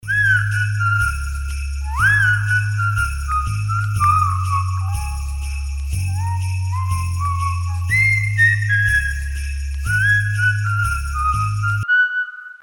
• Качество: 320, Stereo
веселые
без слов
колокольчики